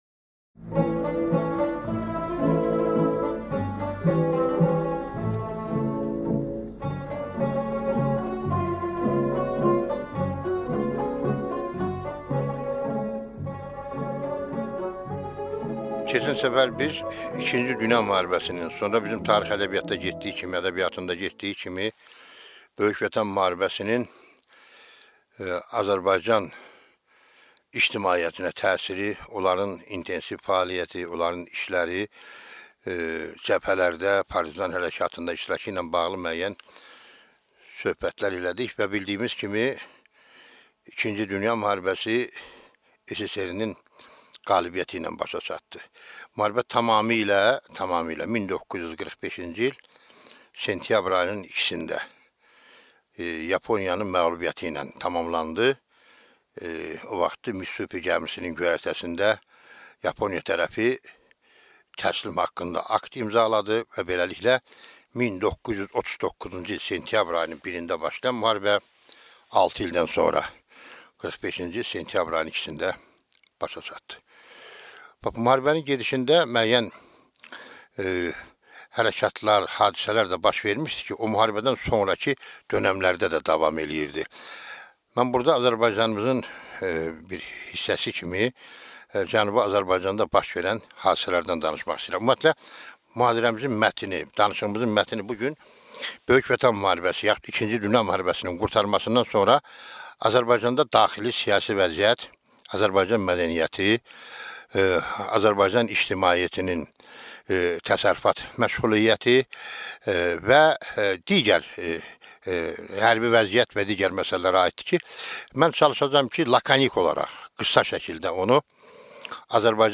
Аудиокнига II Dünya müharibəsindən sonra Azərbaycandakı daxili siyasi vəziyyət | Библиотека аудиокниг